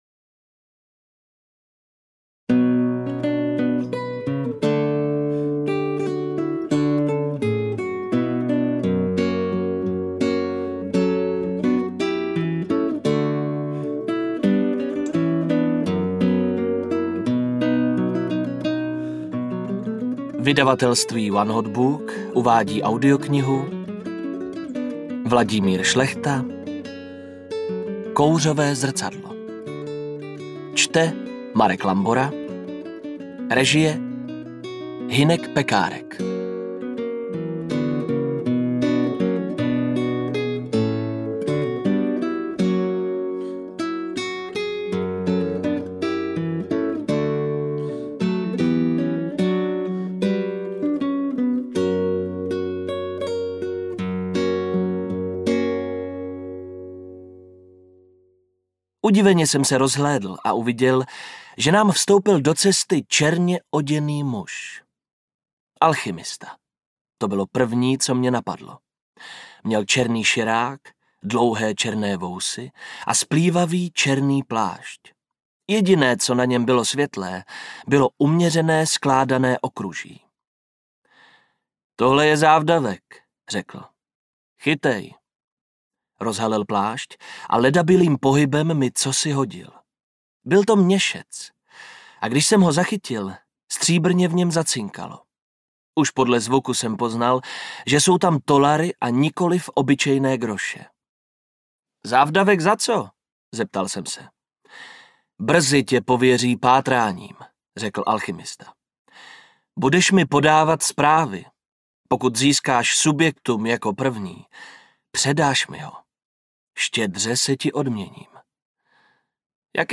Kouřové zrcadlo audiokniha
Ukázka z knihy